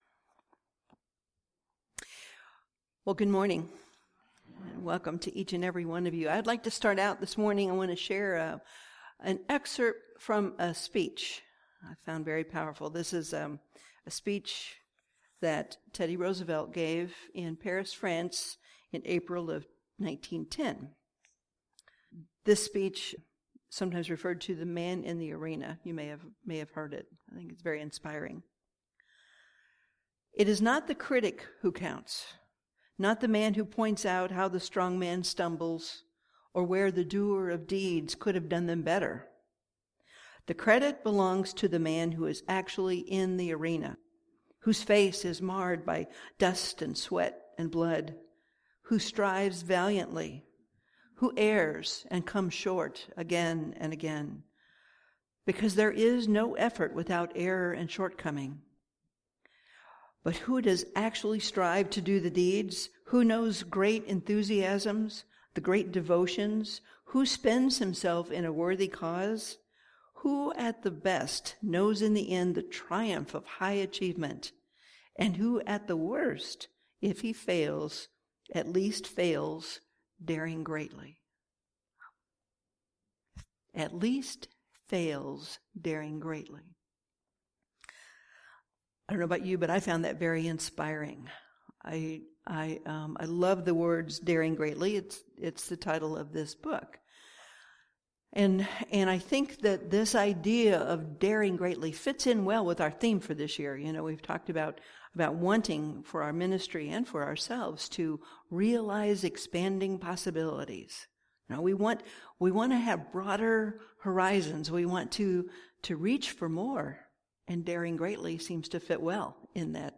Spiritual Communion Service – Church of Light